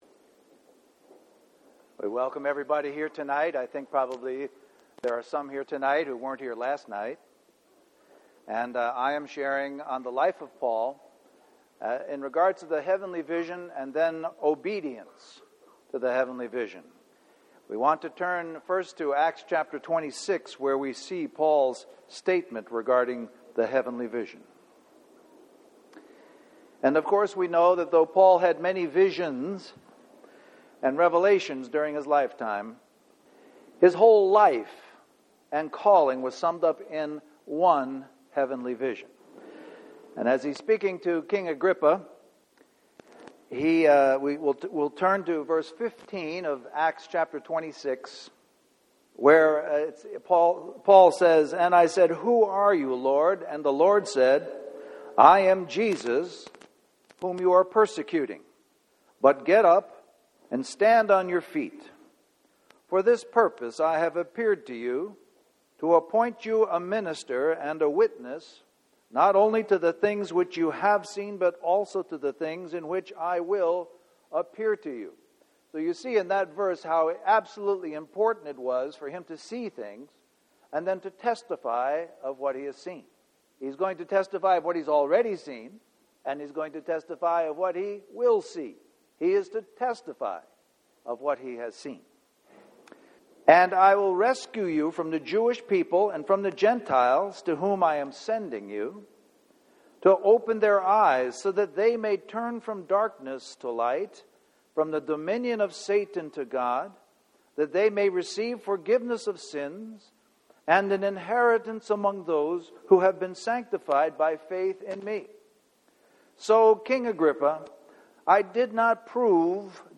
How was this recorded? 2007 Christian Family Conference